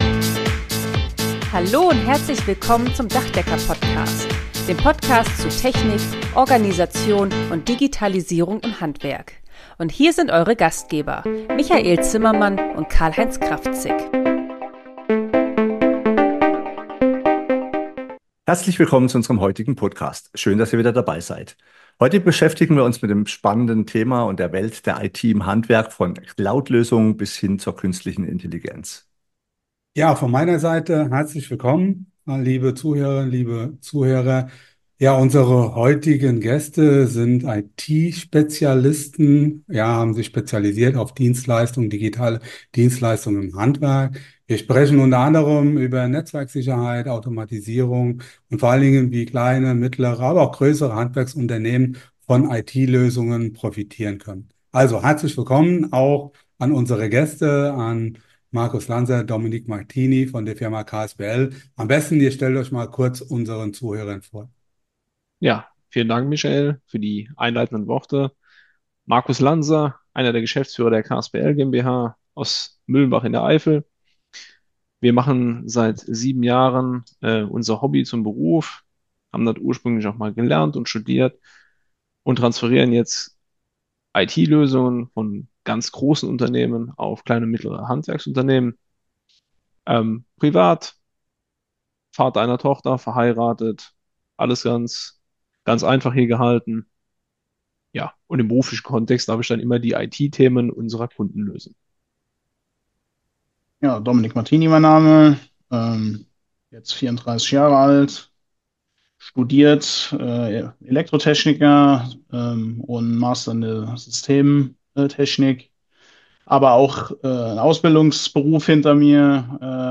Interview mit dem IT Systemhaus KSPL ~ Dachdecker-Podcast Podcast